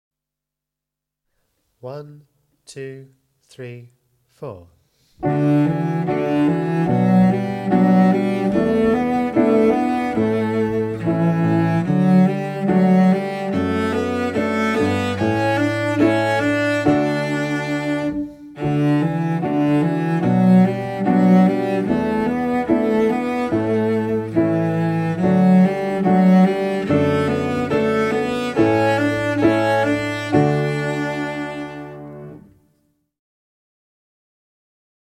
55 Wave machine (Cello)